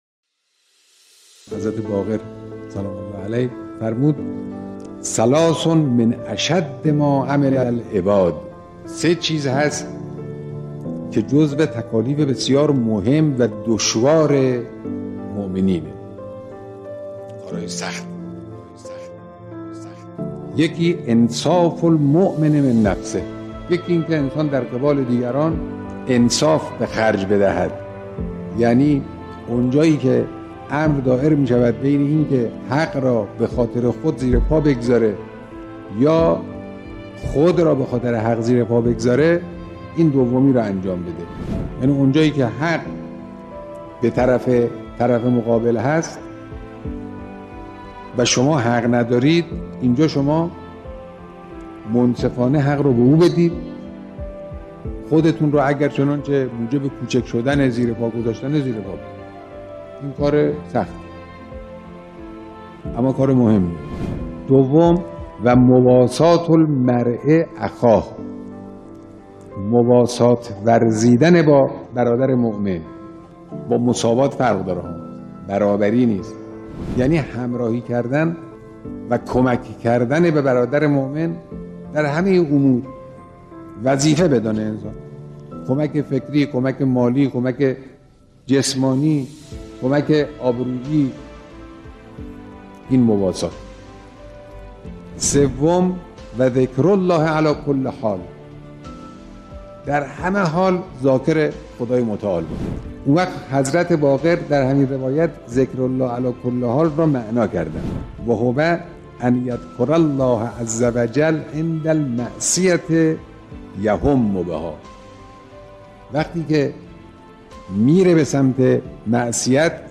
بیانات ارزشمند و دلنشین رهبر معظم انقلاب به مناسبت شهادت امام محمد باقر علیه السلام با عنوان «سرمشق بندگی»